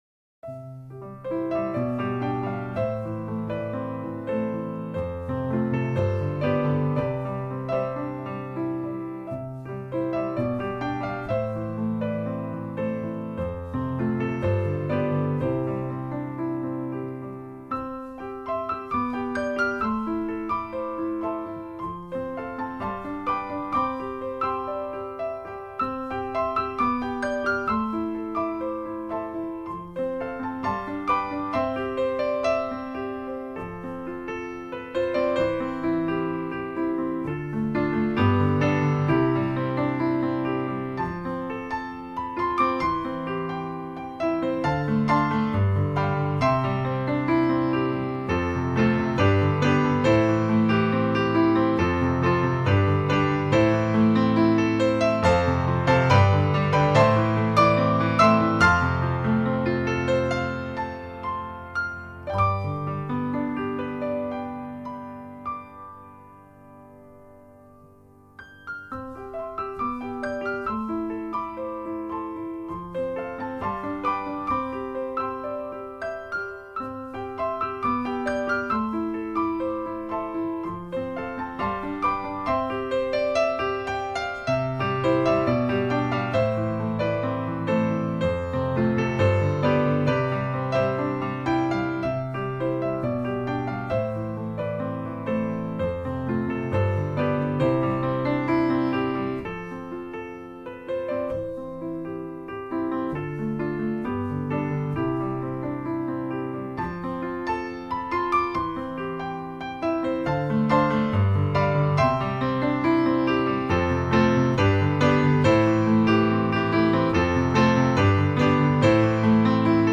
台湾女钢琴家